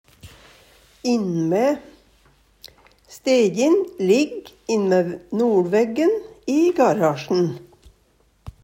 innme' - Numedalsmål (en-US)